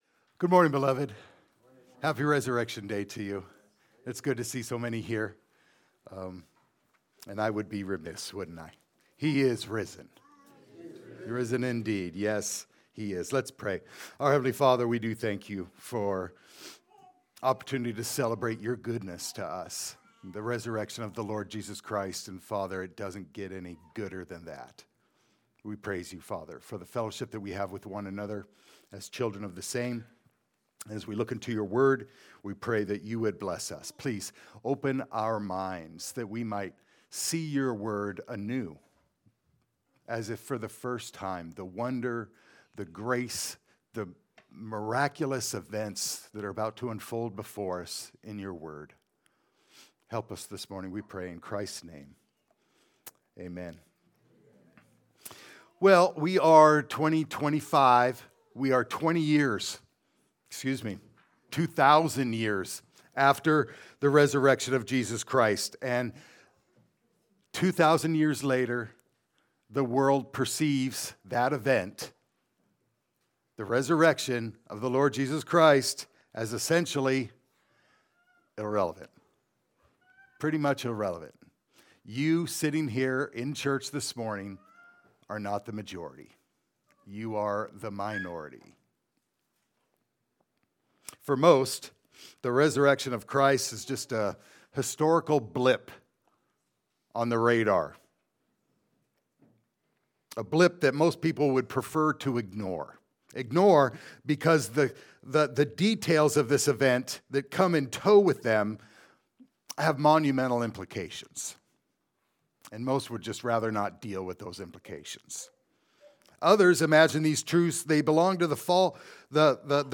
John 20:1-10 Service Type: Easter Sunday Service « “Christ Crucified